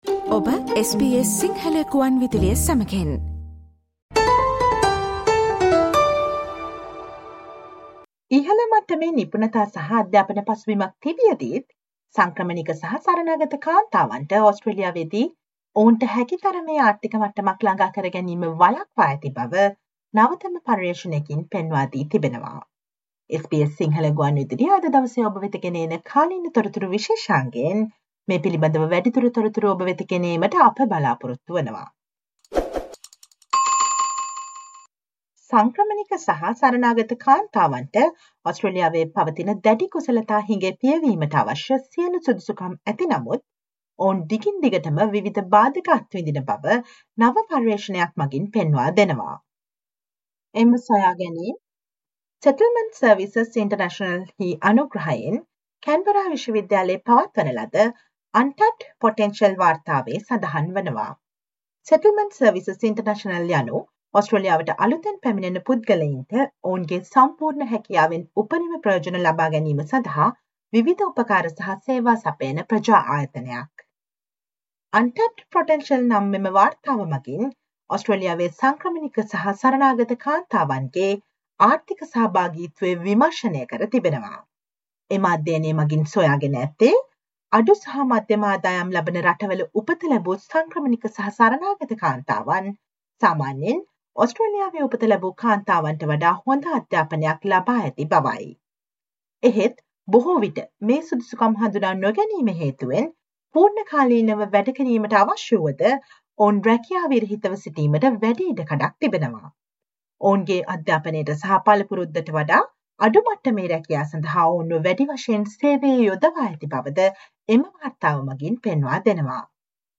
Today - 09 December, SBS Sinhala Radio current Affair Feature on the the potential of highly skilled refugee and migrant women to boost the skill shortage in Australia's economy.